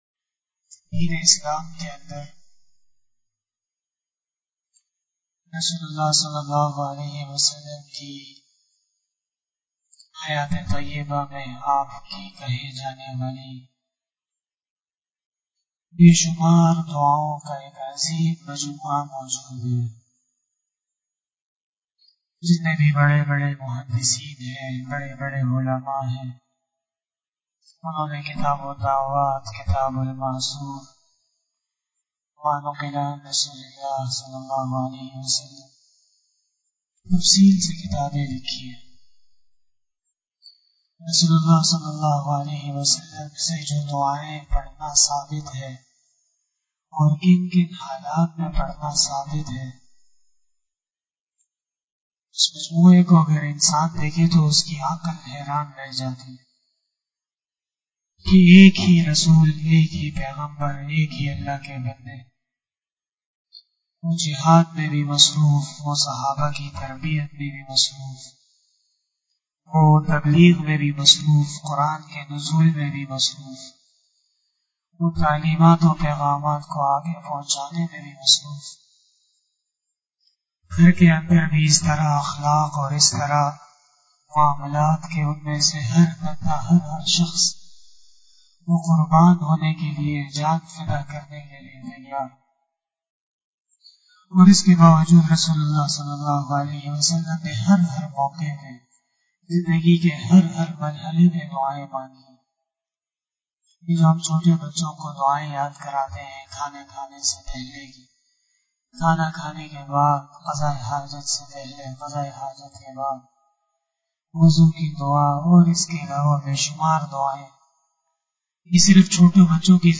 015 After Fajar Namaz Bayan 25 April 2021 ( 12 Ramadan 1442HJ) Sunday